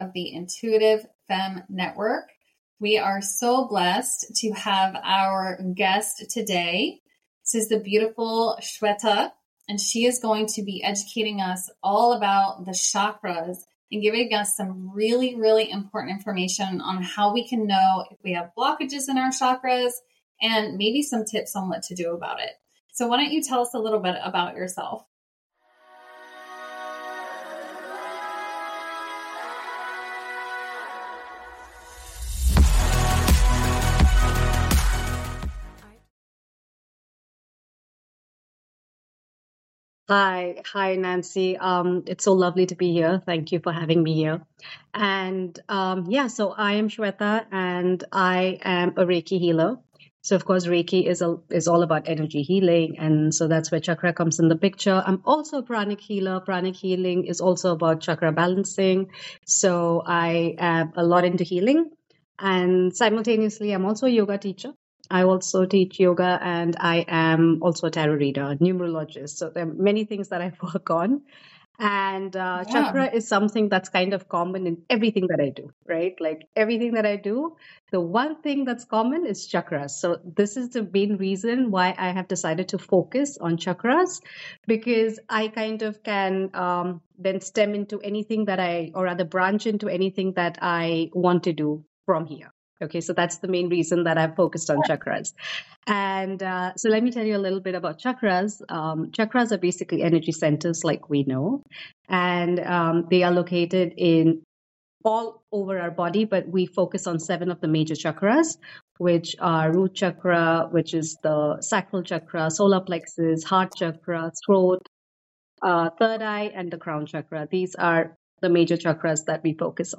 NOTE: Apologies for the minor connectivity issues - the information was too valuable not to share.